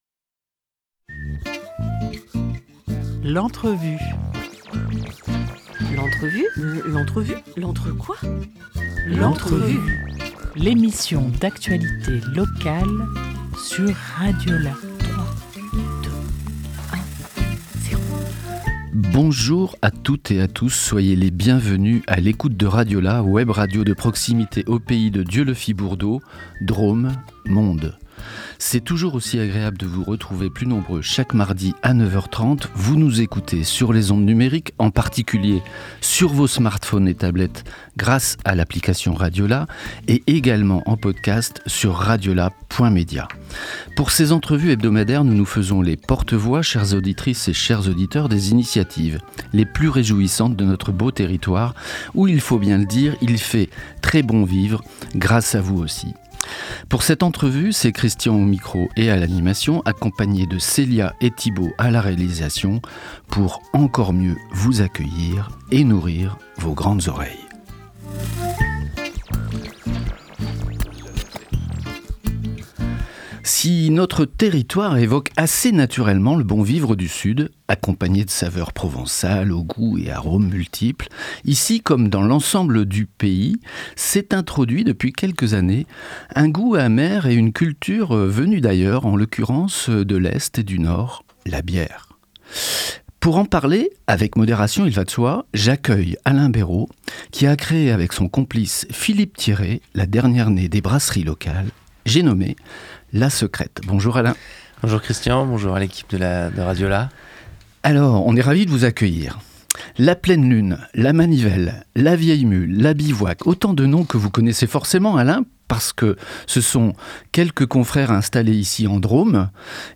27 janvier 2026 10:30 | Interview